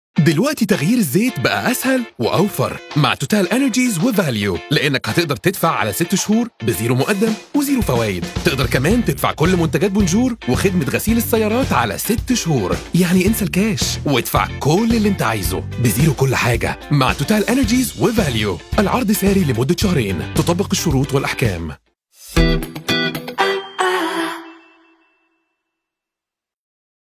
Male Voices